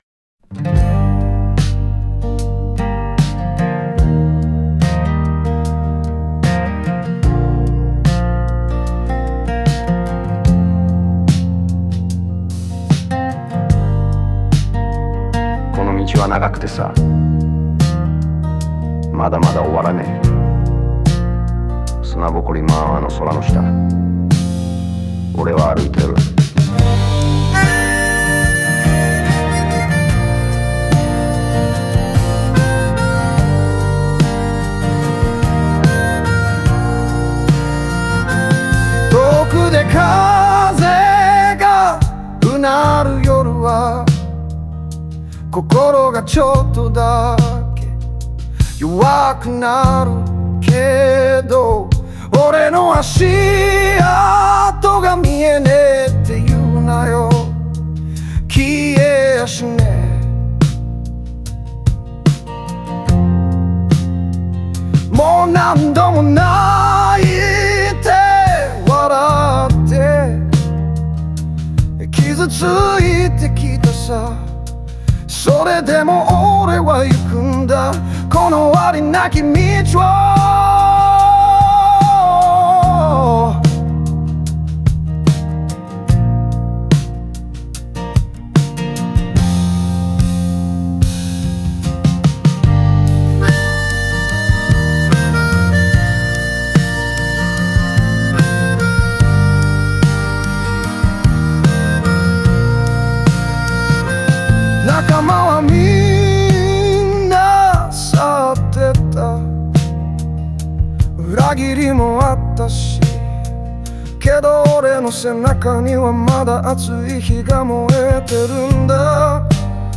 哀愁漂うギターやスライド、荒れた声がその孤独と魂の強さを際立たせる。人生の痛みと希望が交差する、静かで力強い旅の歌だ。